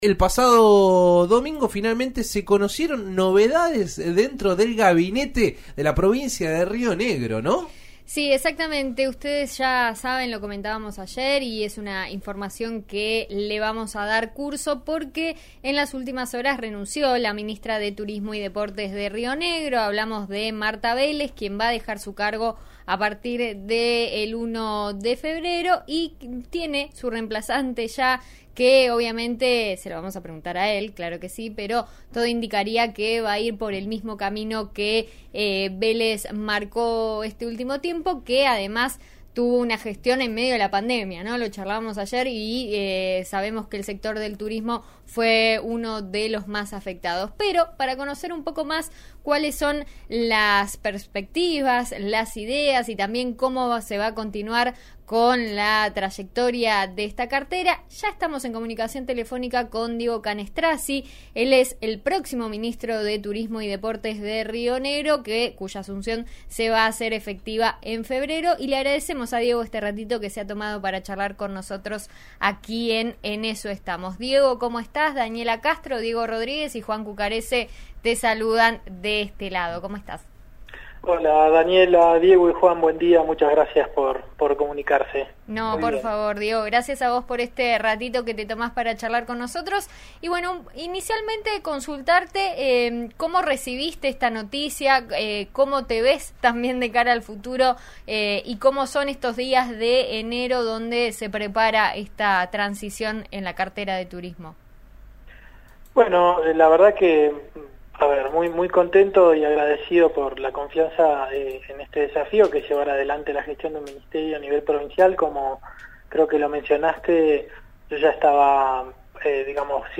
Hoy habló con RÍO NEGRO RADIO.